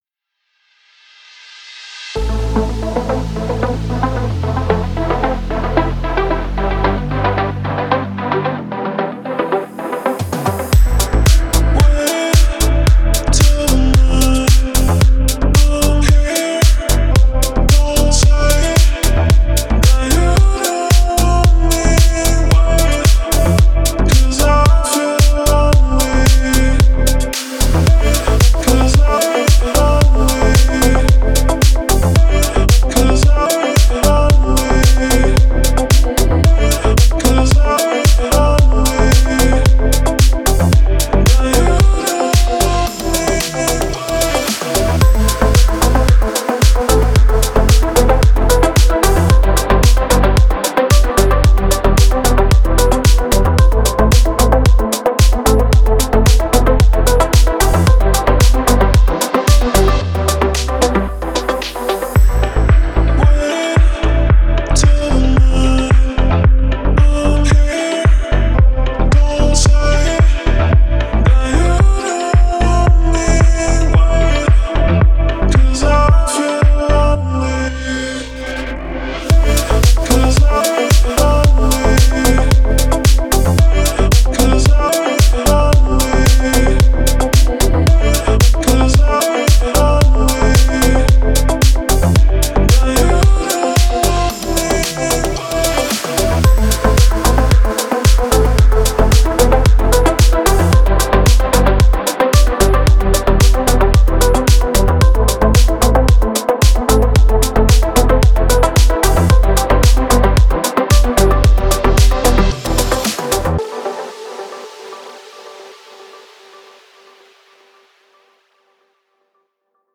Deep House
дип хаус